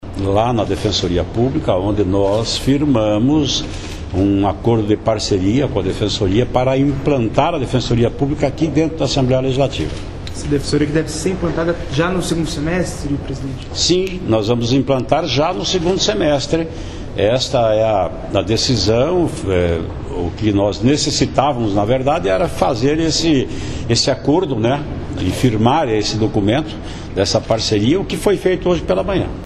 Sonora presidente da Alep, deputado Ademar Traiano (PSDB), sobre a instação de um posto de atendimento da Defensoria Pública do Paraná na Assembleia Legislativa.